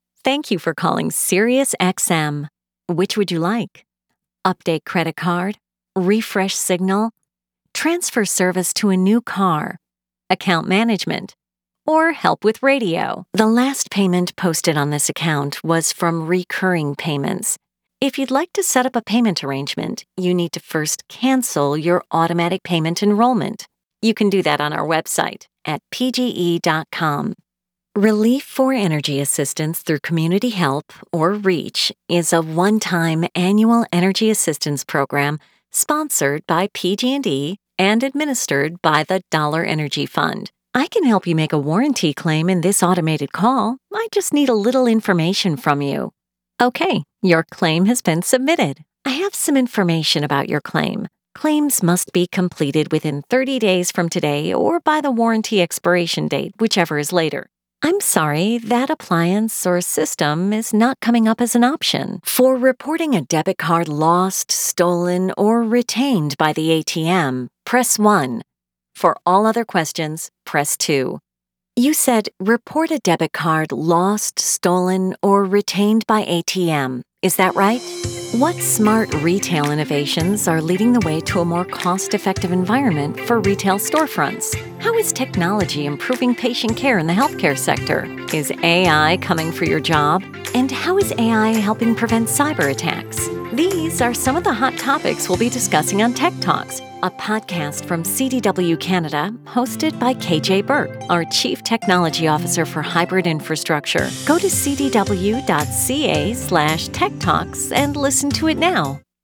Anglais (Américain)
Amicale, Corporative, Naturelle, Accessible, Chaude
Téléphonie